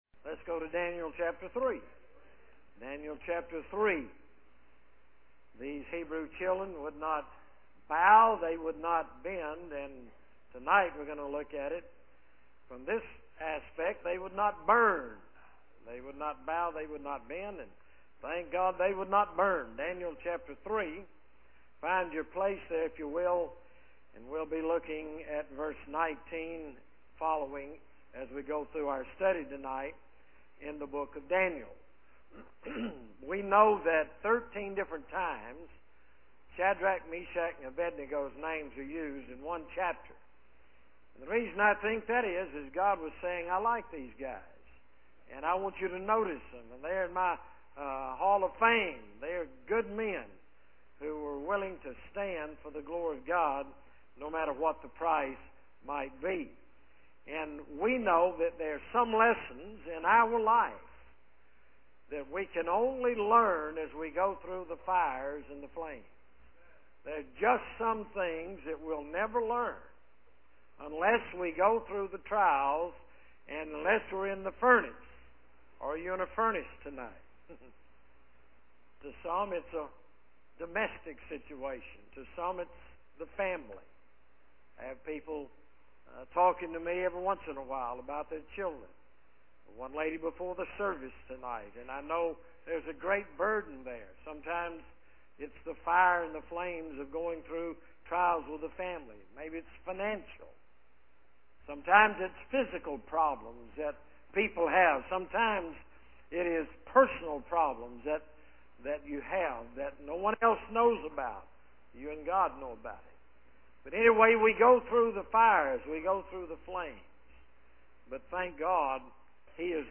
Sermon Audio - Media of Worth Baptist Church